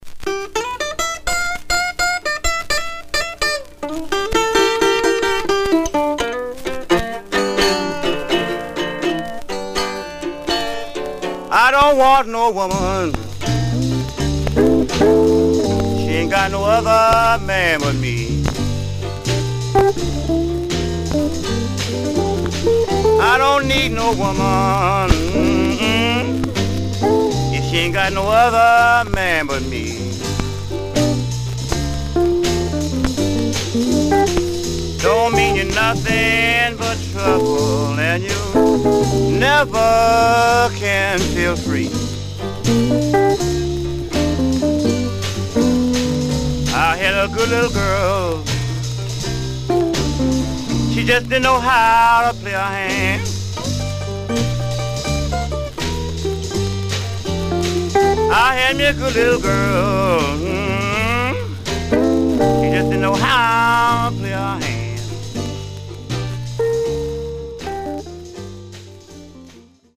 Some surface noise/wear
Mono
Rythm and Blues Condition